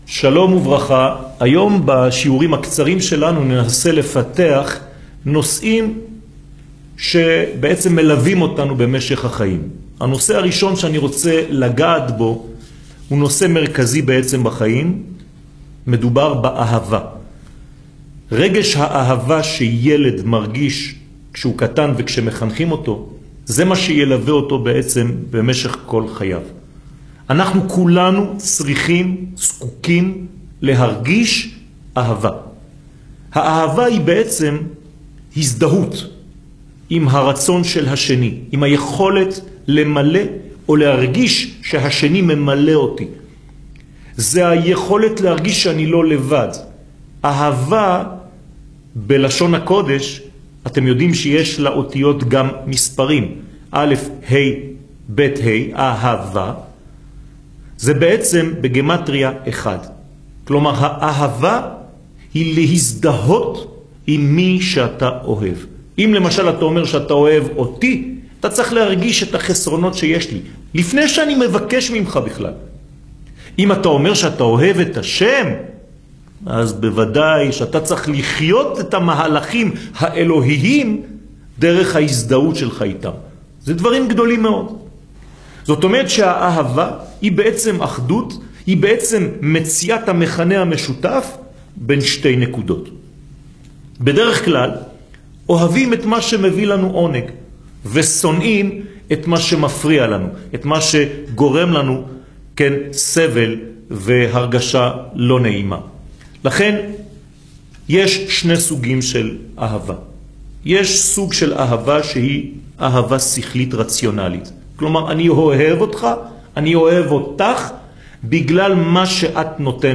שיעורים
שיעורים קצרים